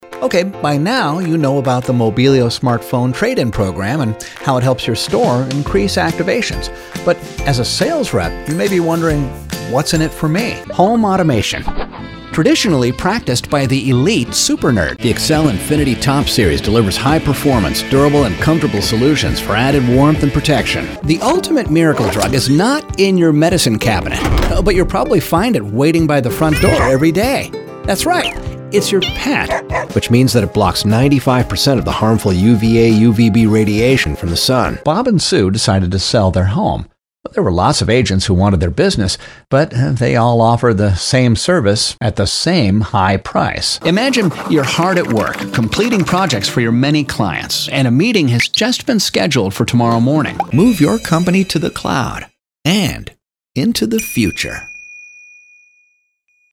Male
Adult (30-50), Older Sound (50+)
Television Spots
Very Conversational Demo